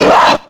Fichier:Cri 0371 XY.ogg — Poképédia
Cri de Draby dans Pokémon X et Y.